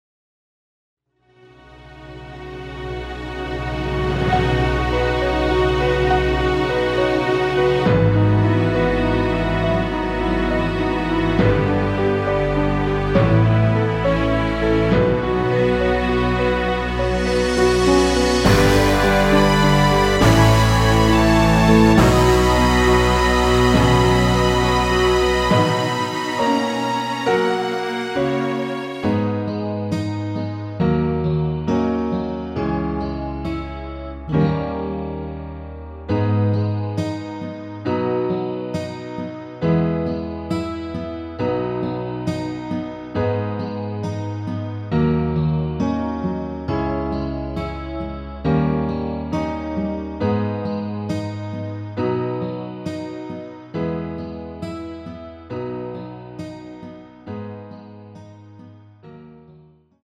엔딩이 길고 페이드 아웃이라 짧게 엔딩을 만들어 놓았습니다.
Abm
◈ 곡명 옆 (-1)은 반음 내림, (+1)은 반음 올림 입니다.
앞부분30초, 뒷부분30초씩 편집해서 올려 드리고 있습니다.
중간에 음이 끈어지고 다시 나오는 이유는